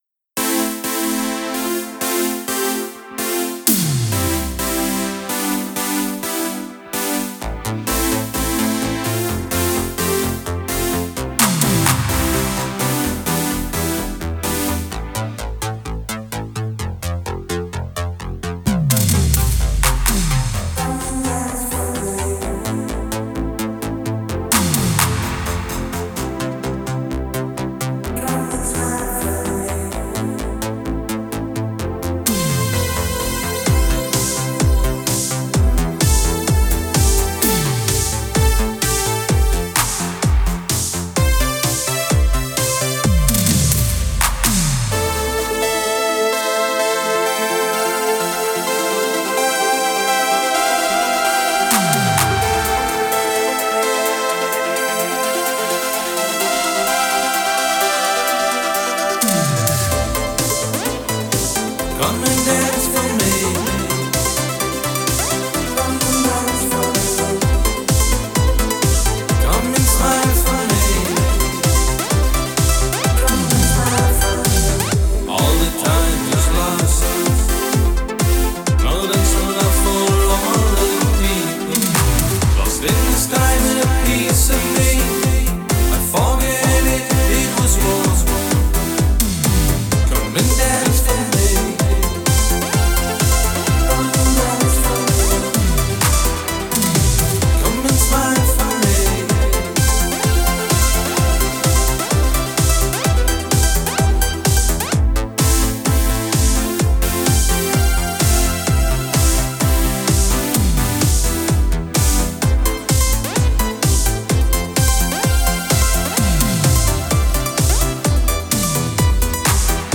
Synthpop · Synthwave · CD & Vinyl
Extended Version · WAV & MP3